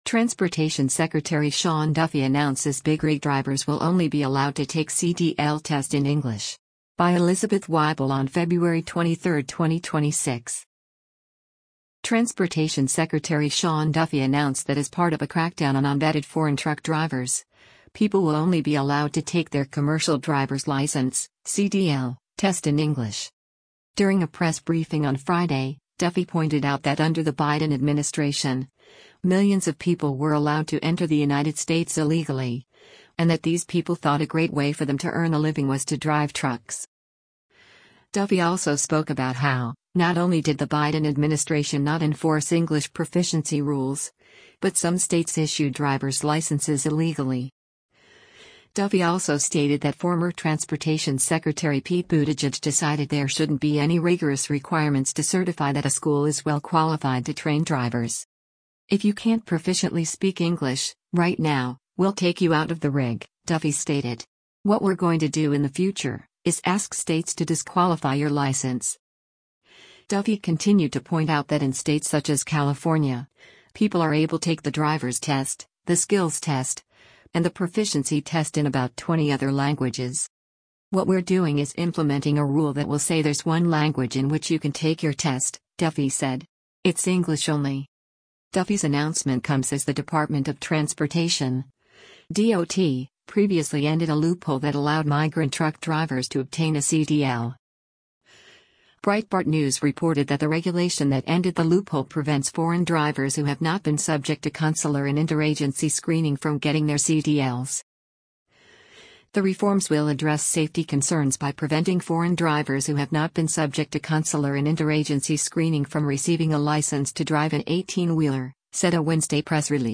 During a press briefing on Friday, Duffy pointed out that under the Biden administration, millions of people were allowed to enter the United States illegally, and that these people thought “a great way” for them to earn a living was to drive trucks.